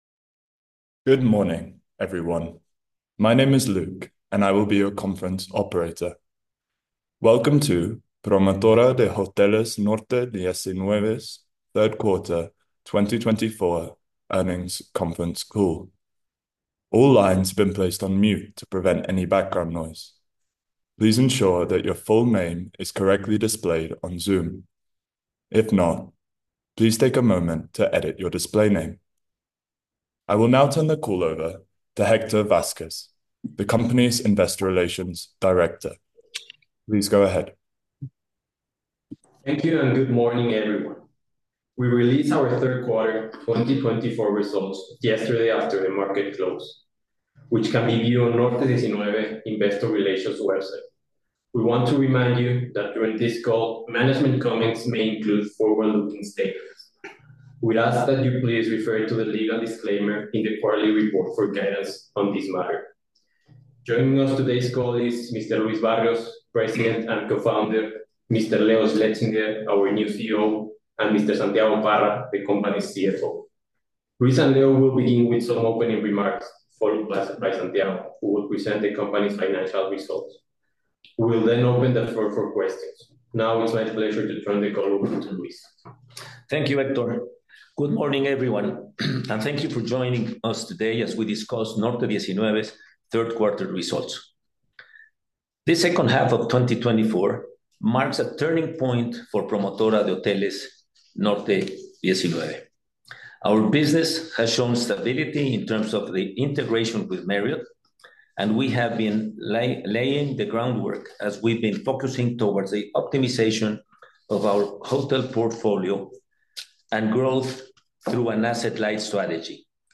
Norte-19-3Q24-Conference-Call-Audio.mp3